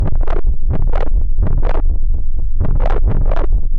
Clicky Texturized Techno bass.wav
Original creative-commons licensed sounds for DJ's and music producers, recorded with high quality studio microphones.
clicky_texturized_techno_bass__rej.ogg